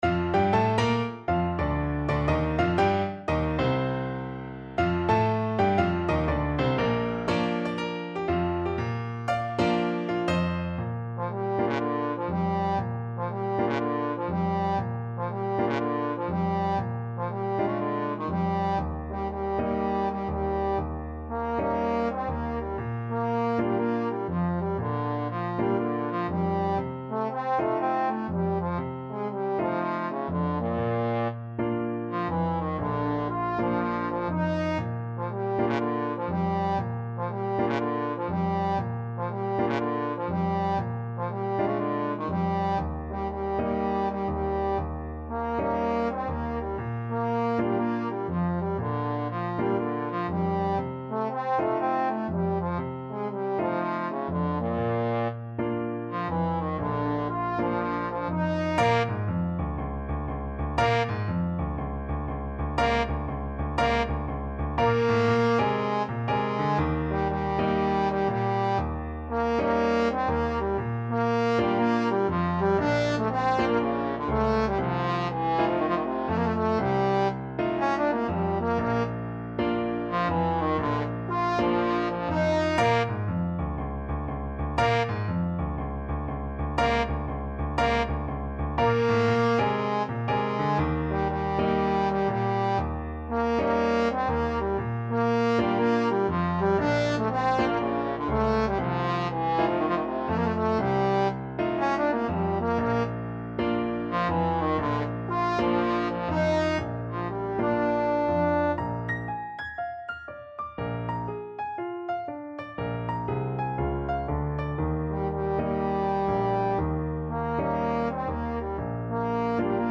4/4 (View more 4/4 Music)
Moderato = 120
Jazz (View more Jazz Trombone Music)